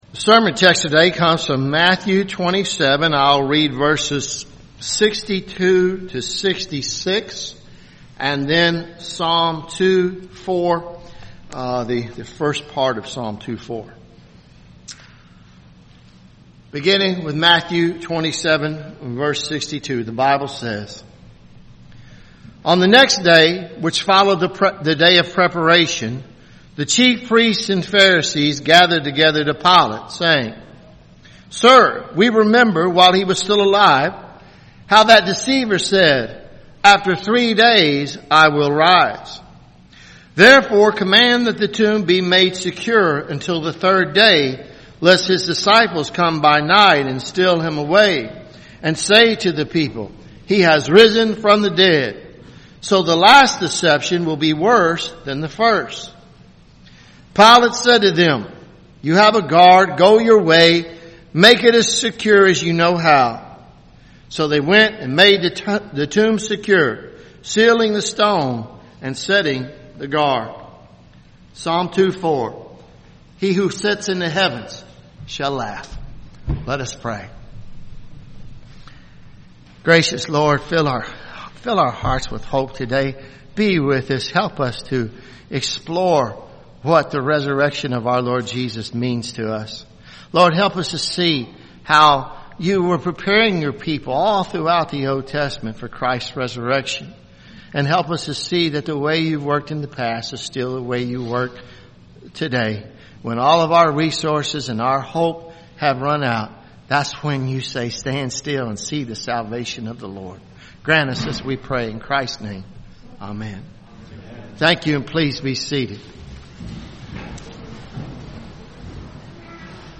Sermons Apr 01 2018 “April Fools